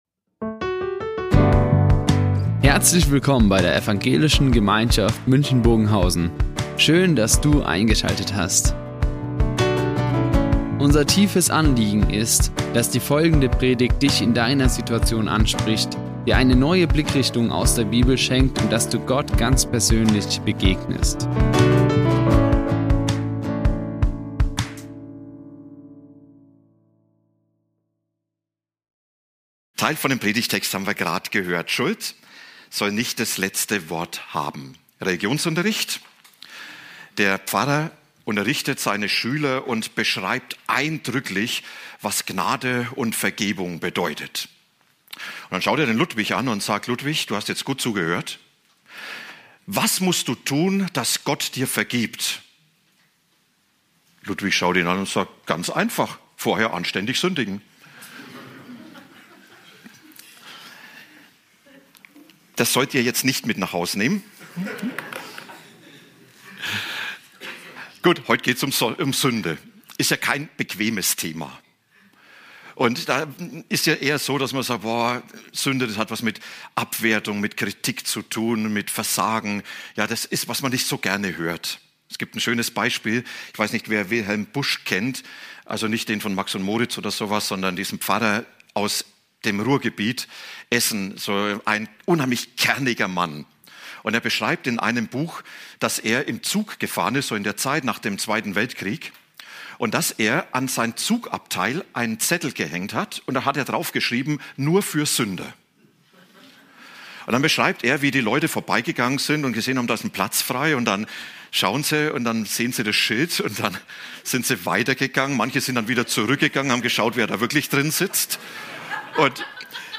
Die Aufzeichnung erfolgte im Rahmen eines Livestreams.